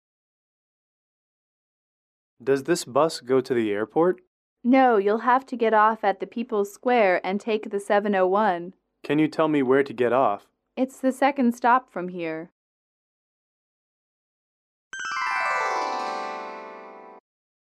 英语主题情景短对话02-1：机场问路（MP3）